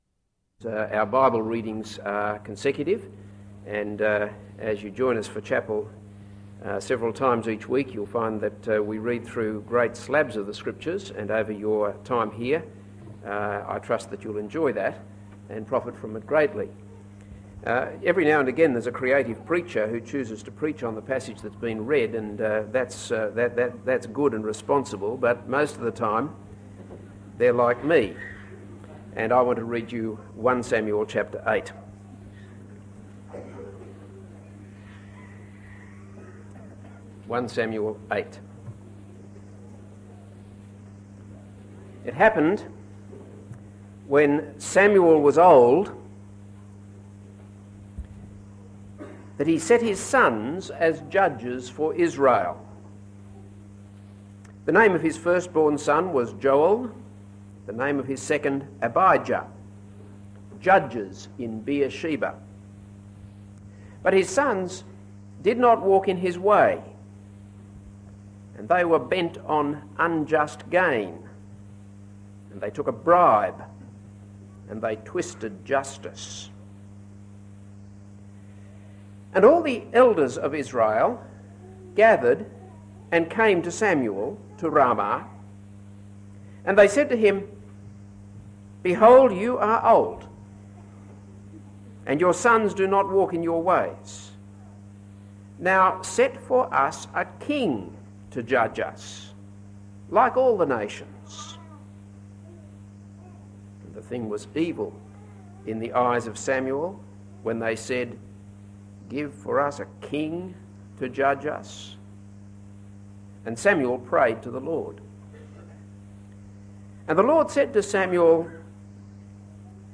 This is a sermon on 1 Samuel 8.